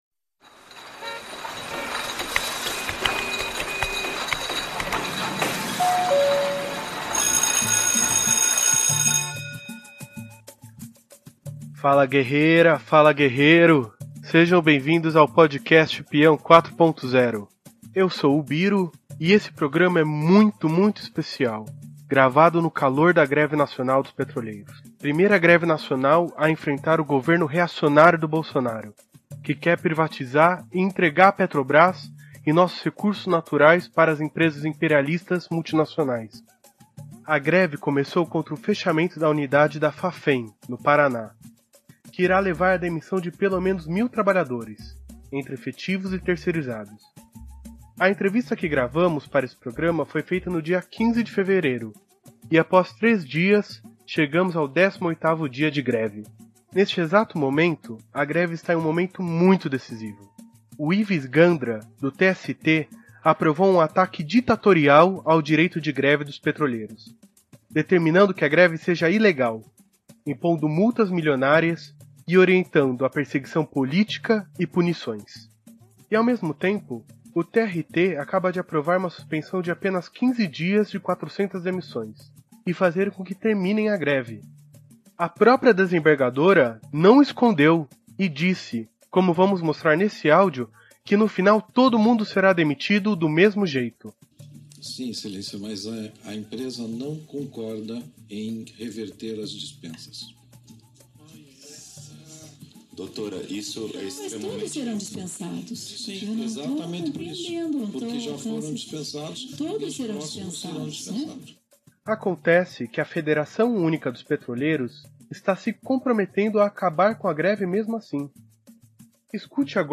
Nesse episódio nº4 do Peão 4.0 trazemos uma conversa entre dois petroleiros que estão numa importante greve nacional que estão fazendo contra as demissões no Paraná e o projeto de privatização do governo Bolsonaro, que com o apoio do Tribunal Superior do Trabalho quer entregar...